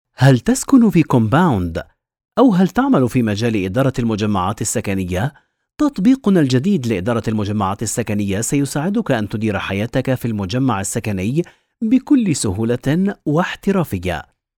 Narración
E-learning
Vídeos corporativos
Adulto joven
Mediana edad